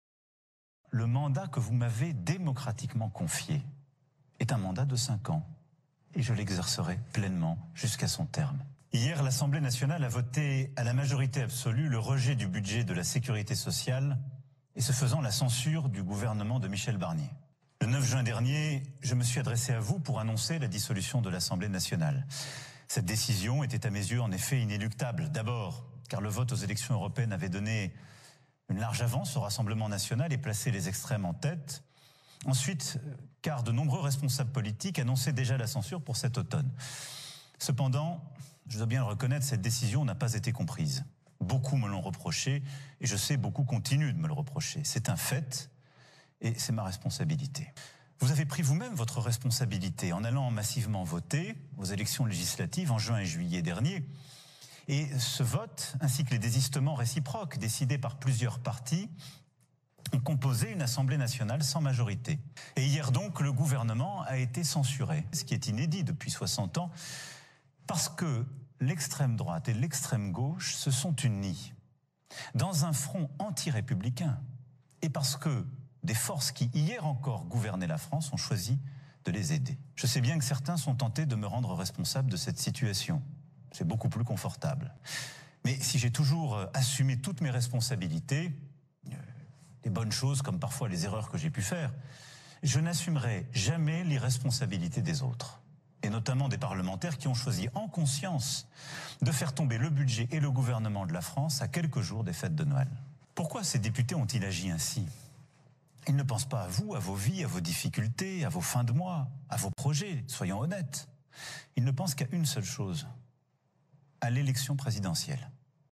Слушаем отличный французский Президента Франции Эмманюэля Макрона. Рассуждаем на тему политических событий.
C2-Allocution-dEmmanuel-Macron.mp3